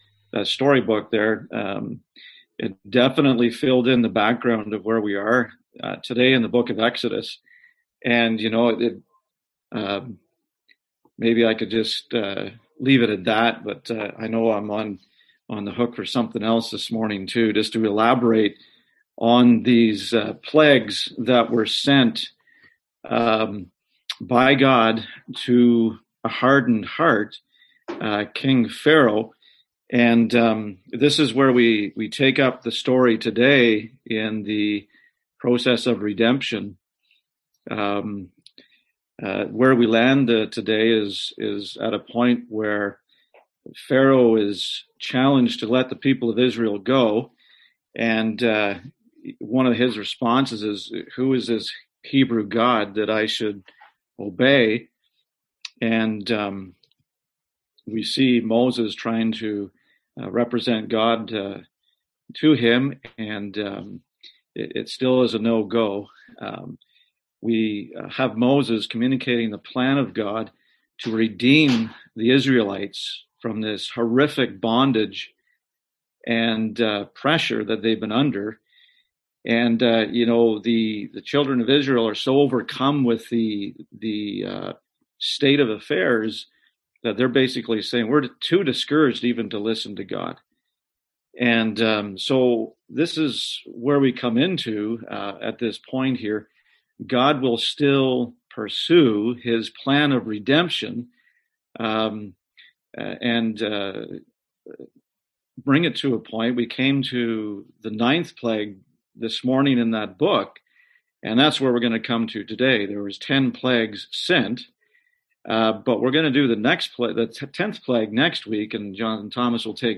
Series: Exodus 2021 Passage: Exodus 6:28-10:29 Service Type: Sunday AM